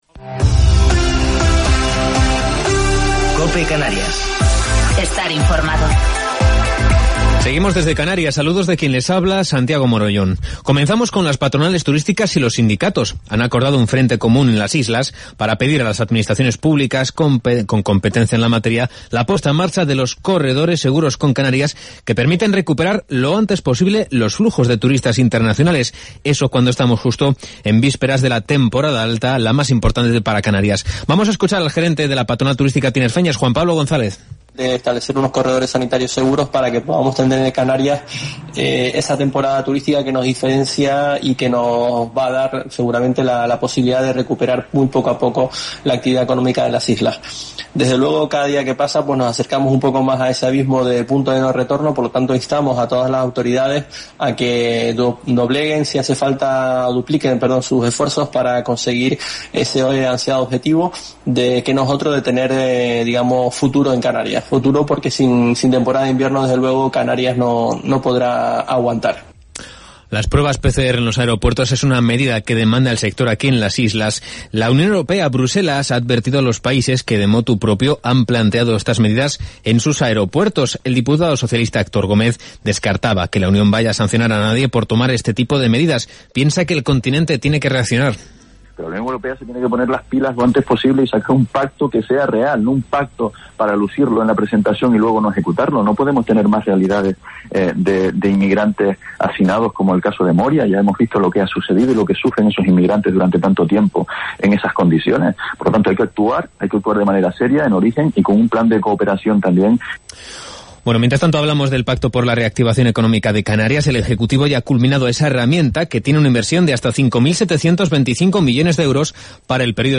Informativo local 18 de Septiembre del 2020